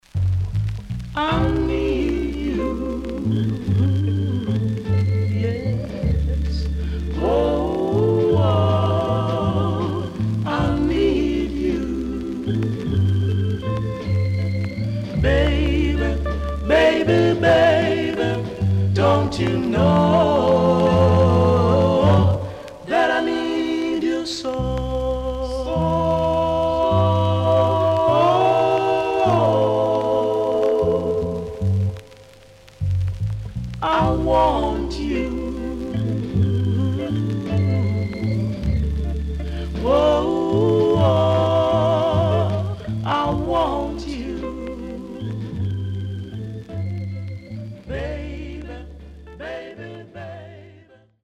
CONDITION SIDE A:VG(OK)〜VG+
SIDE A:軽くプレスノイズあります。